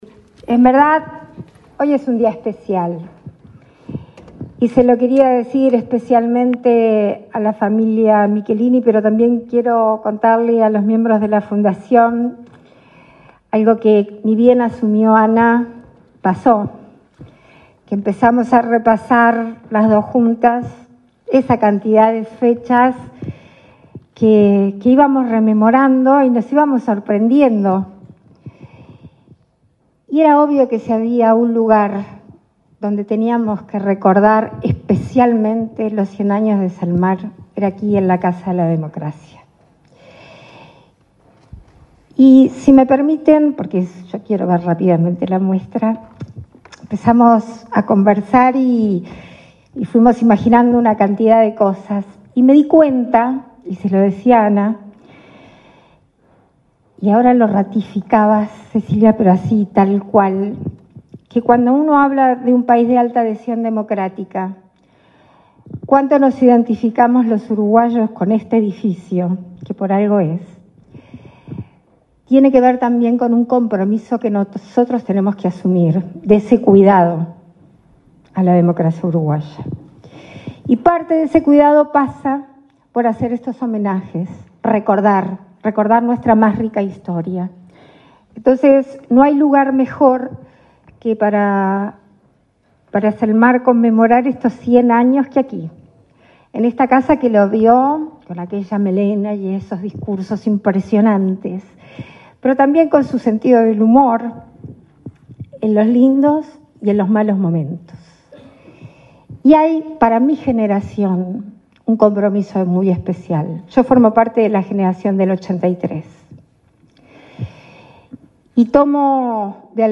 Palabras de la presidenta en ejercicio, Beatriz Argimón, en muestra Zelmar Michelini
Palabras de la presidenta en ejercicio, Beatriz Argimón, en muestra Zelmar Michelini 09/09/2024 Compartir Facebook X Copiar enlace WhatsApp LinkedIn La presidenta en ejercicio, Beatriz Argimón, participó, este lunes 9 en el Palacio Legislativo, de la inauguración de la muestra "Zelmar Michelini-100 años".